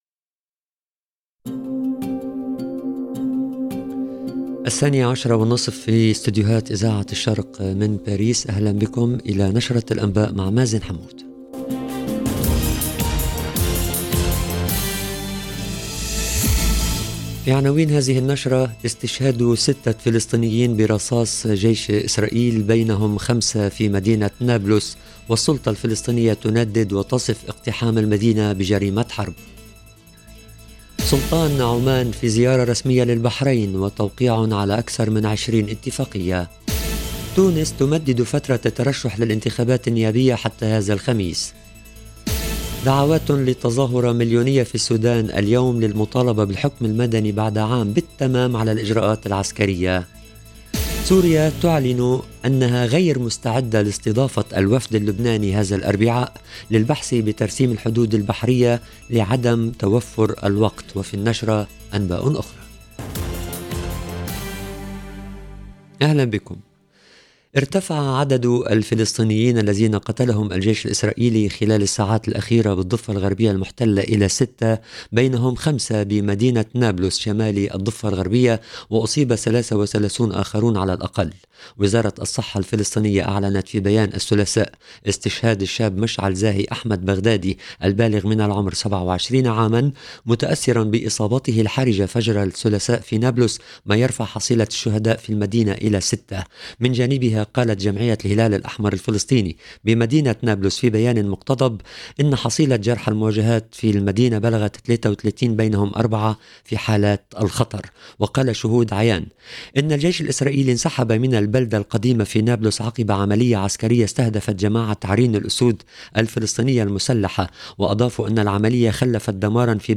LE JOURNAL EN LANGUE ARABE DE 12H30 DU 25/10/22